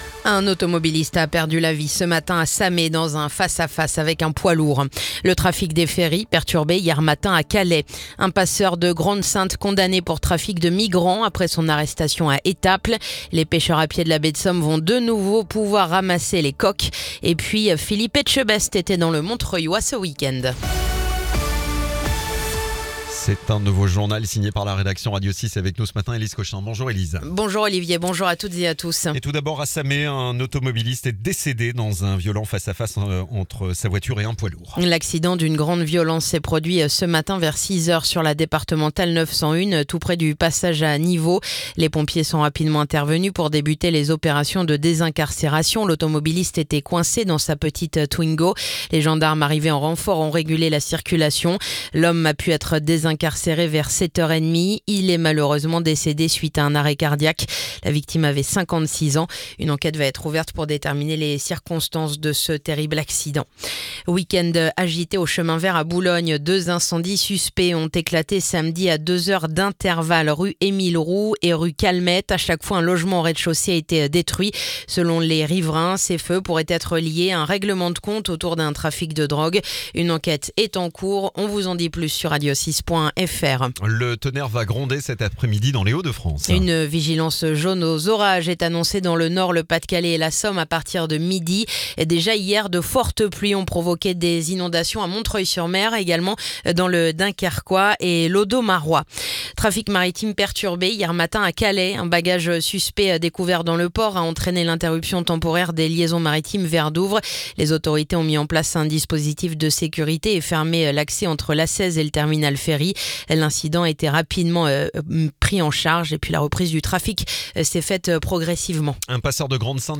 Le journal du luni 21 juillet